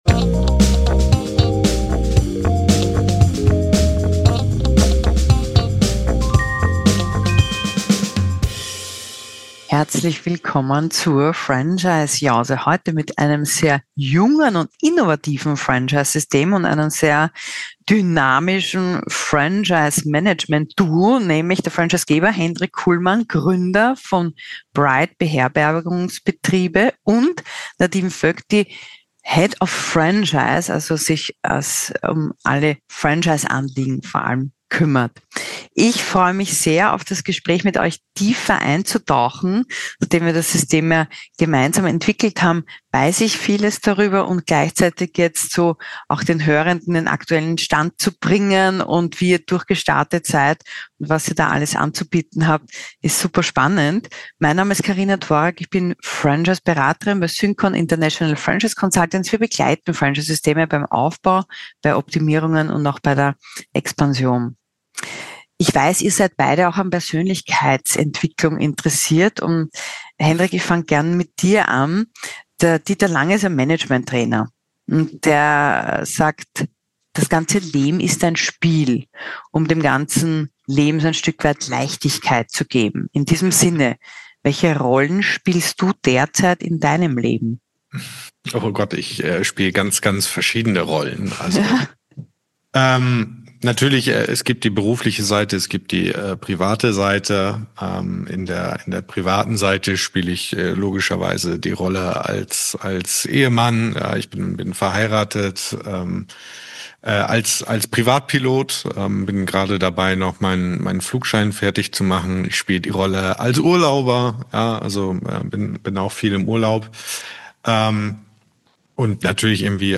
Ein Gespräch über Kurzzeitvermietung, Wachstum, Automatisierung, Know-How Übertrag und Quereinsteiger